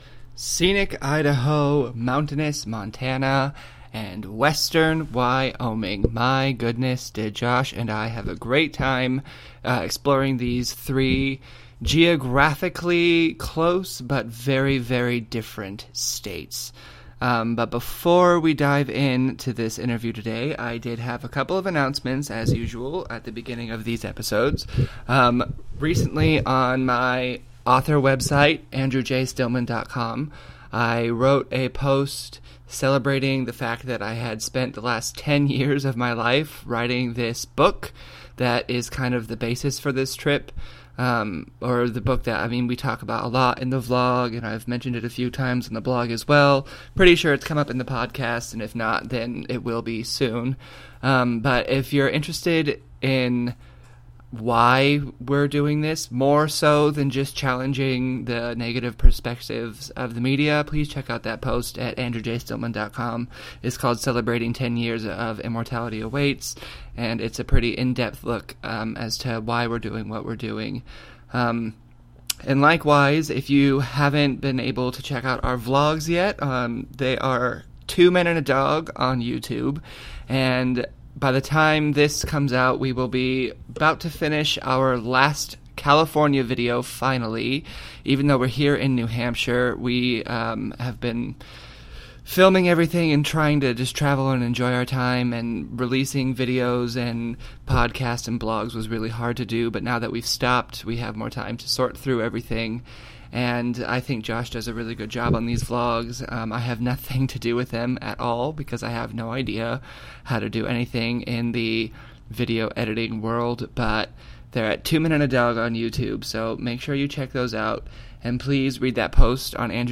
In continuing our conversation recorded in this podcast